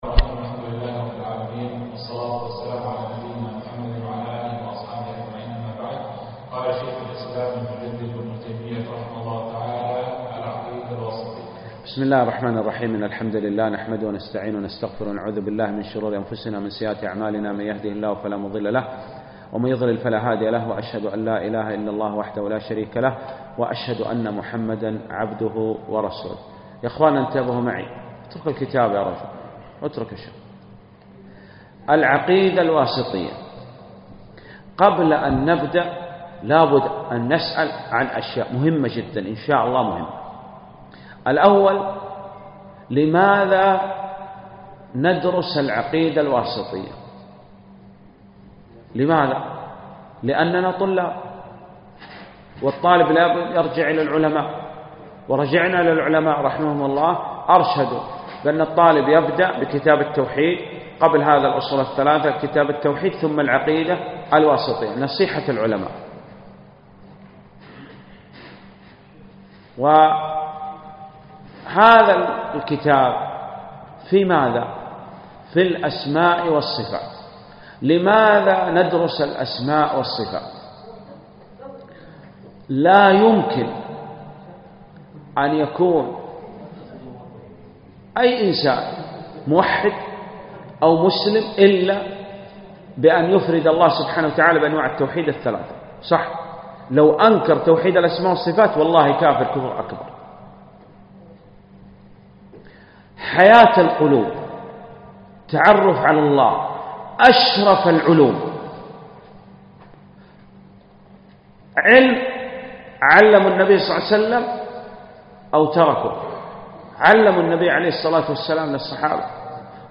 الدرس الاول